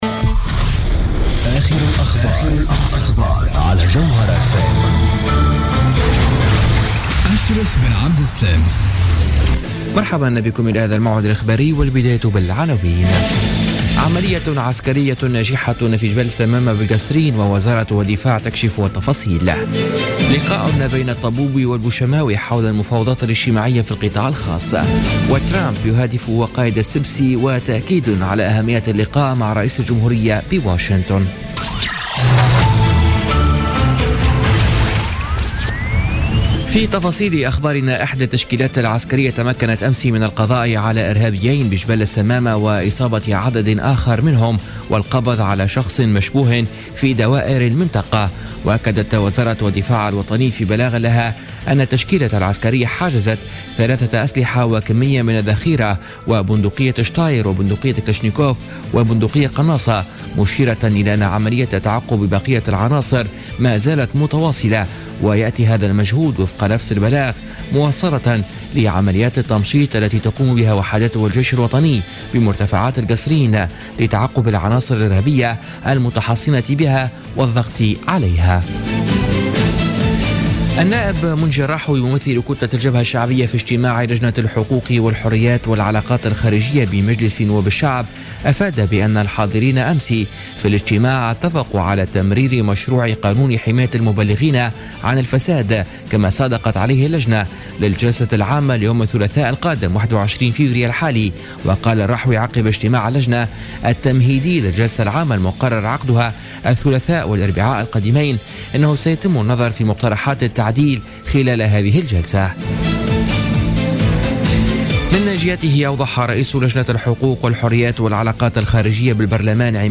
نشرة أخبار منتصف الليل ليوم السبت 18 فيفري 2017